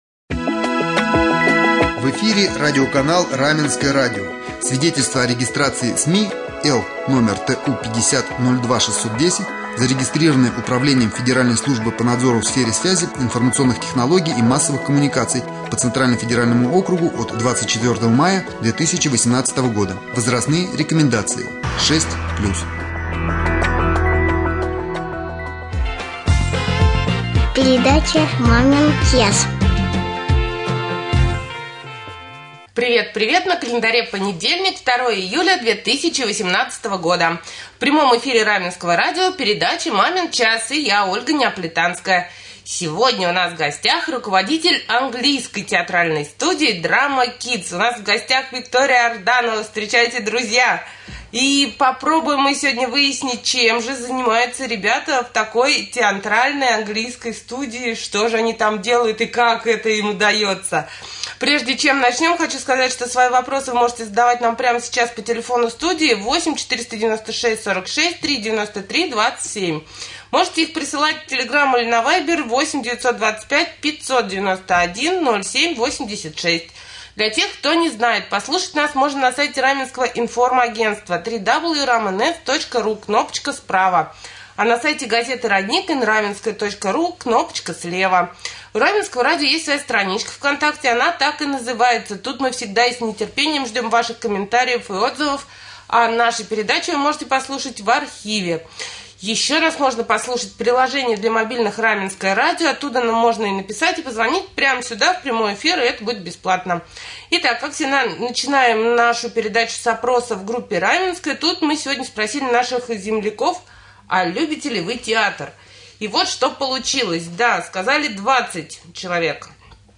Гостья студии